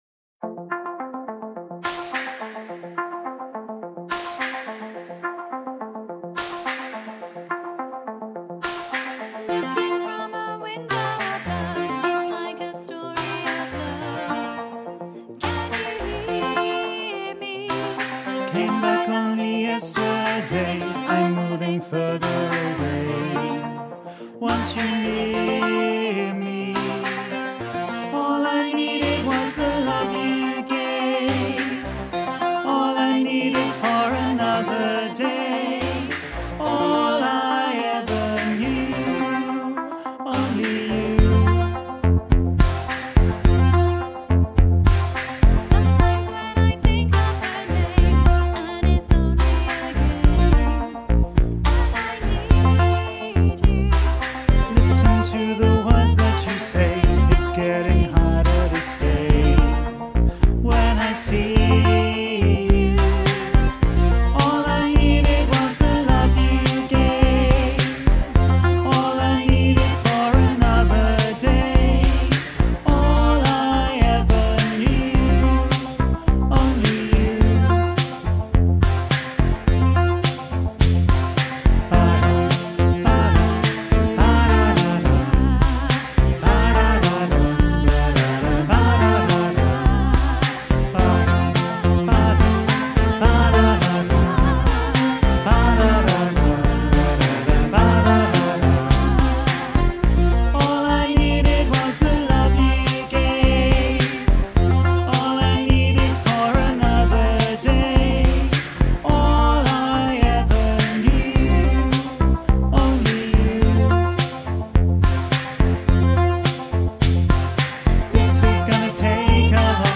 Listen to bass track with soprano and alto accompaniment
3-only-you-voice-2-low-half-mix.mp3